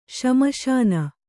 ♪ śmaśana